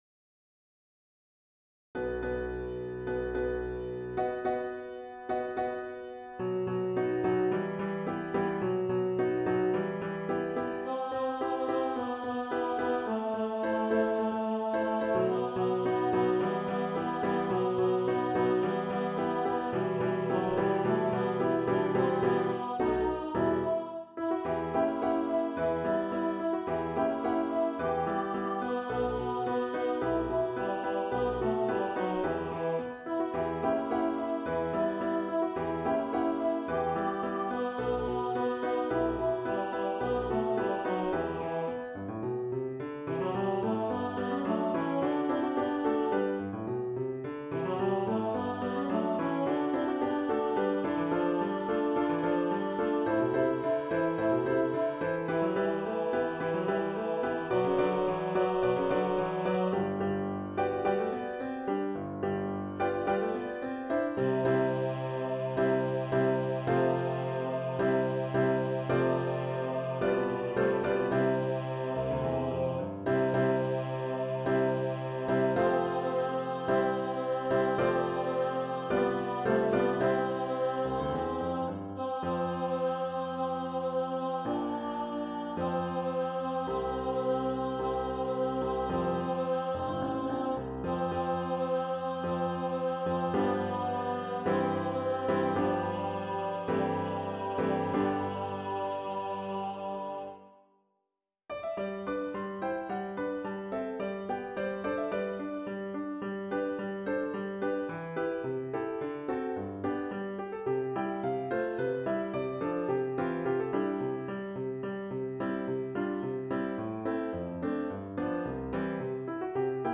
Zigeunerleben Tenor.wav